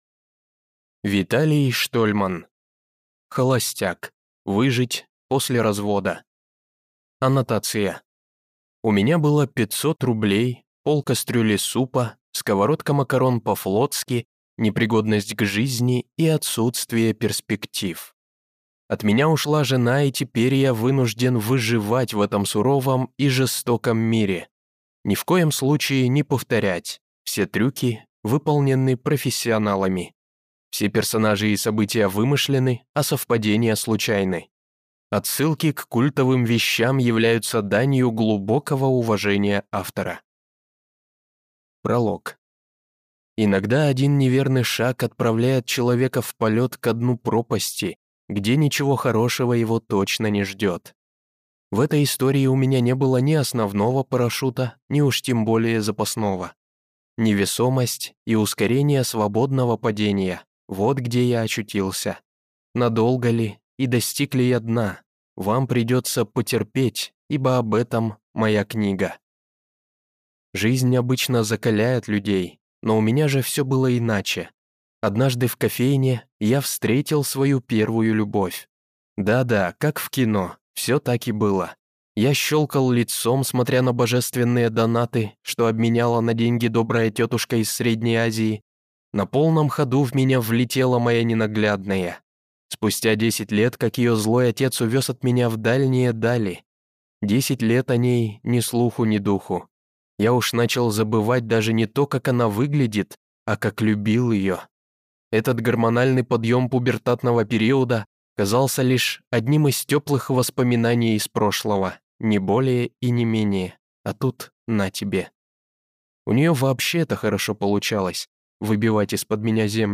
Аудиокнига Холостяк. Выжить после развода | Библиотека аудиокниг